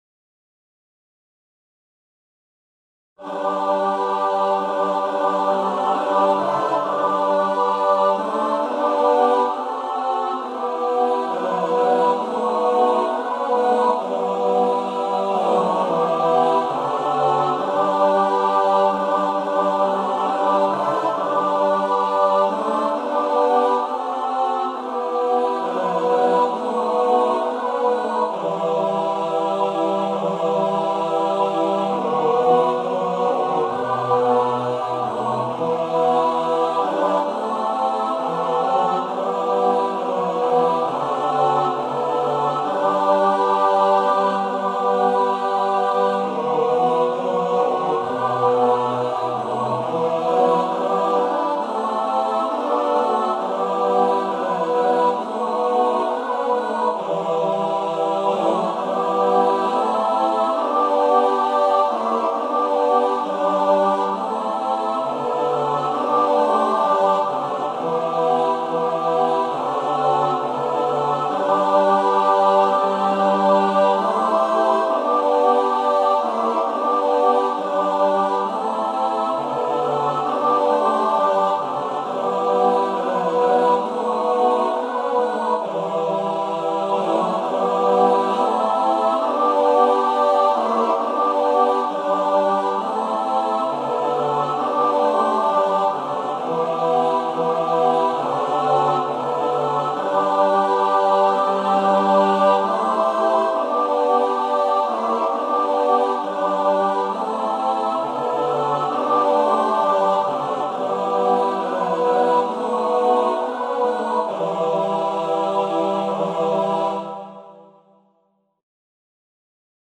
Hymne Oekraine - instrumental
hymne-oekraine-instrumental.mp3